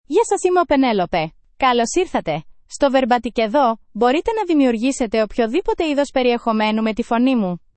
PenelopeFemale Greek AI voice
Penelope is a female AI voice for Greek (Greece).
Voice sample
Female